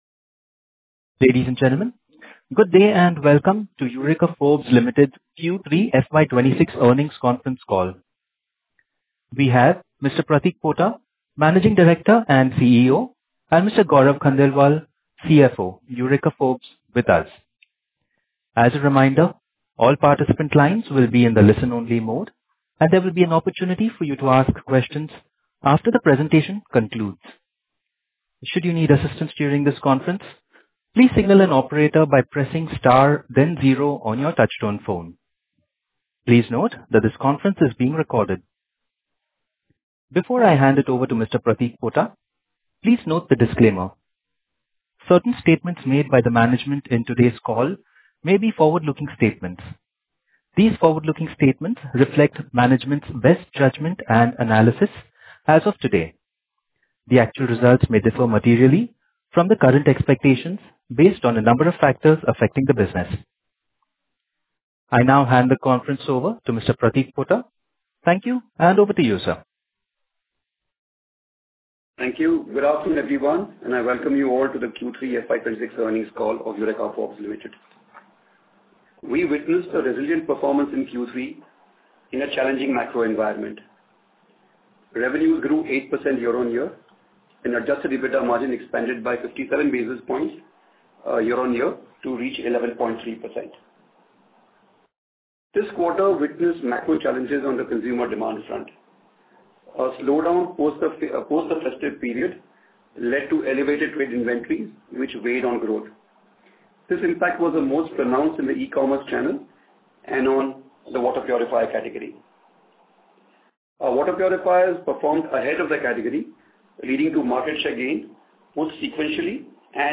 Concalls
Analysts_and_Earnings_Call_Q3FY26.mp3